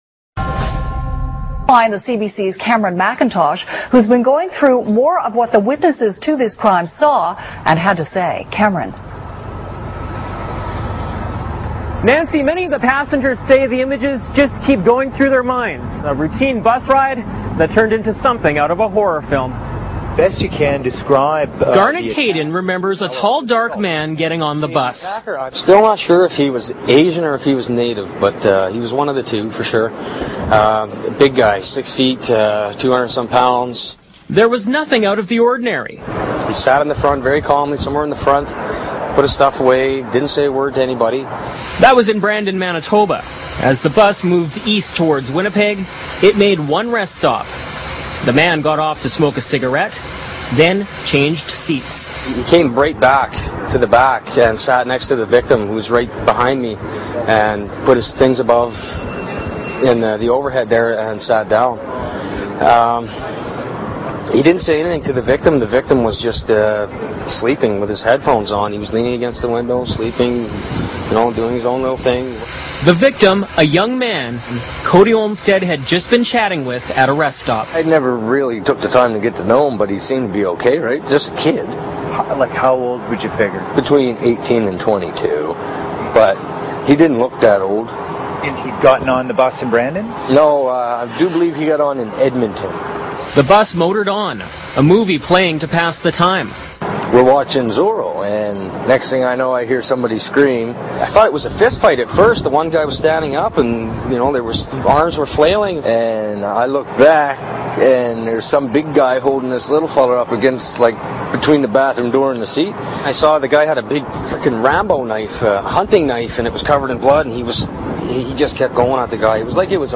Man Decapitated – News Clip